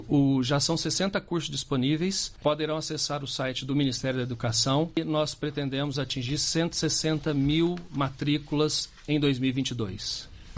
São cursos gratuitos de forma online, como explica Tomás Sant’Ana – secretário de Educação Profissional e Tecnológica.
Sonora-Tomas-SantAna-secretario-de-Educacao-Profissional-e-Tecnologica.mp3